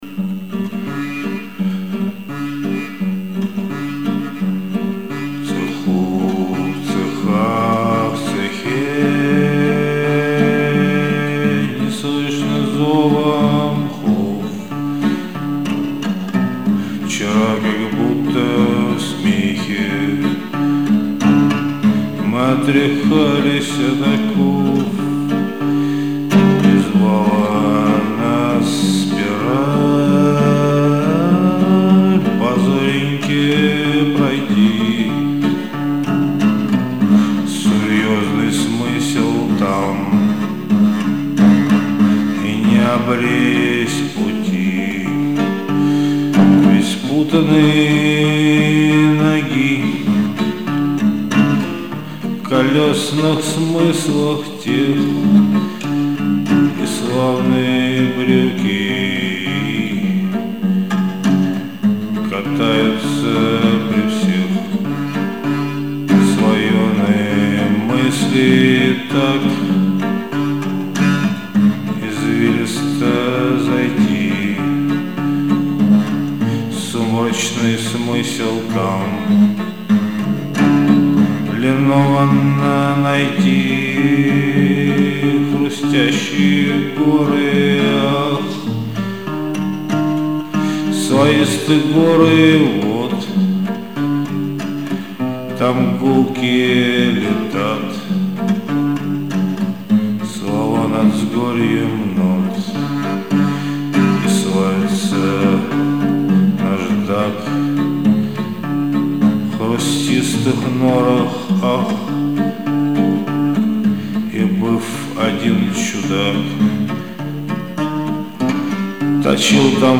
Достаточно заунывно, все как я люблю...
Стихи читать невозможно, а гитара и непонятный вокал даже и ничего.